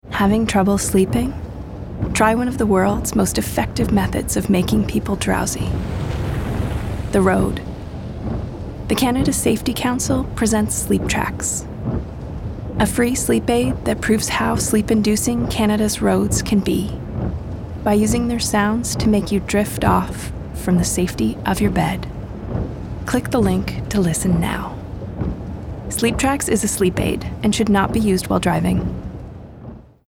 This led to the creative idea to allow drivers to safely experience the road’s sleep-inducing effects for themselves by recording the sounds of some of Canada’s most monotonous and dangerous roads and turning them into highly-effective aural sleep aids (each lasting over 8 hours).